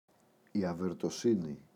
αβερτοσύνη, η [averto’sini]